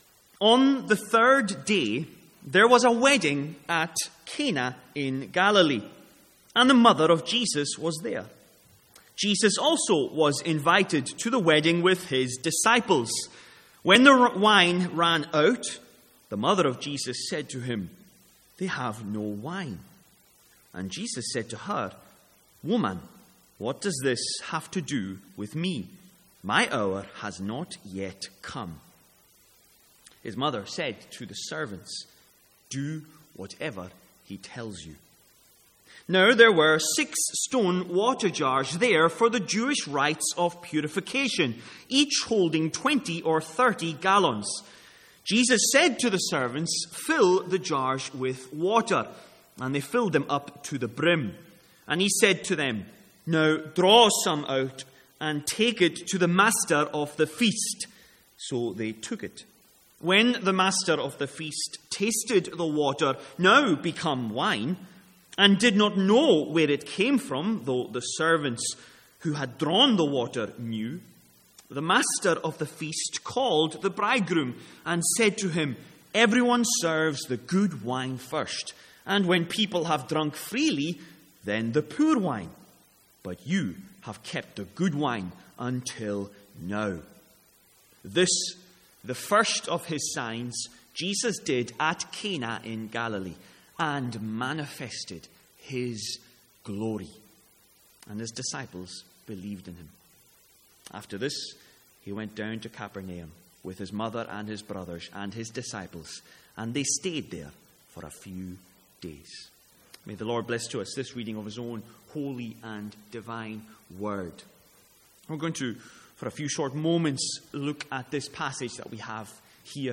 Sermons | St Andrews Free Church
From the Sunday evening series in John.